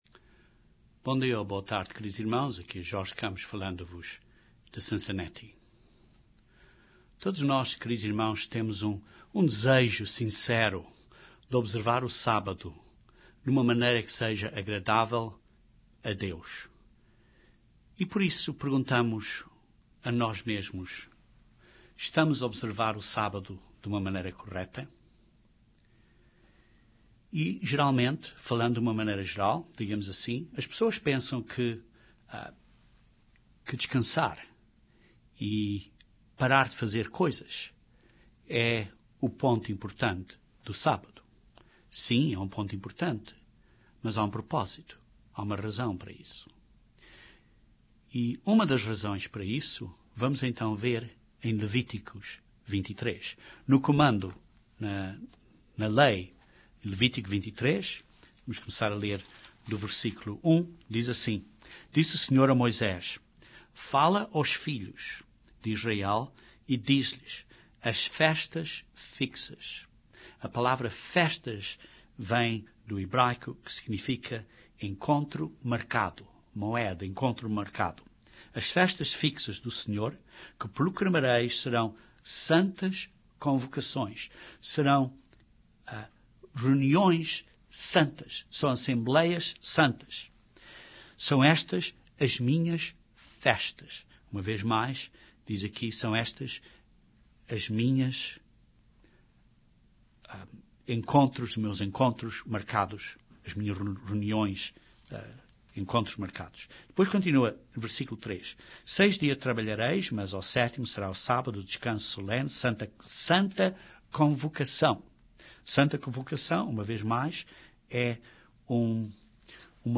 O nosso desejo é de observar o Sábado corretamente. Este sermão descreve o valor da convivência Cristã e demonstra que é uma parte importantíssima para observar o Sábado de acordo com o mandamento.